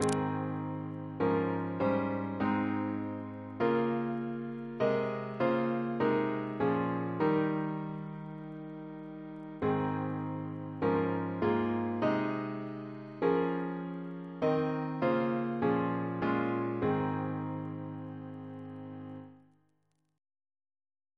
CCP: Chant sampler
Double chant in A♭ Composer: Chris Biemesderfer (b.1958)